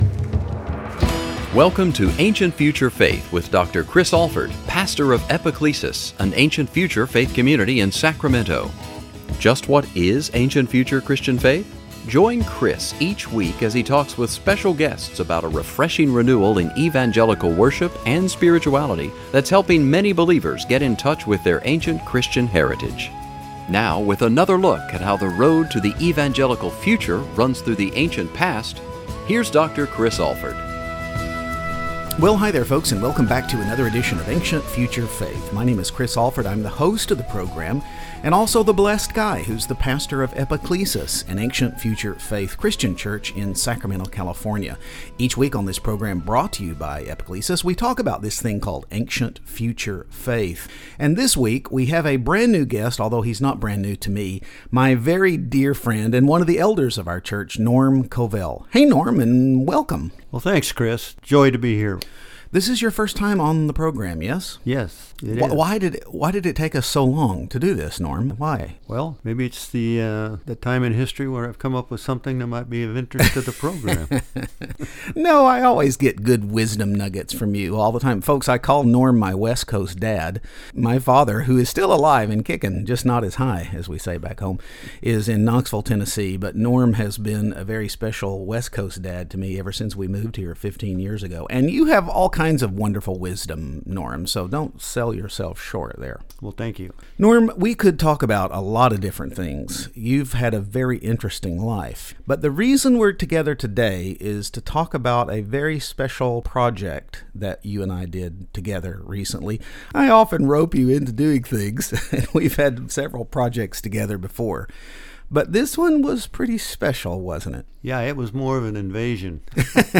What lessons were learned along the way? Join us for a fun conversation about a huge project in styrofoam!